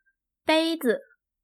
bēizi
ベイズ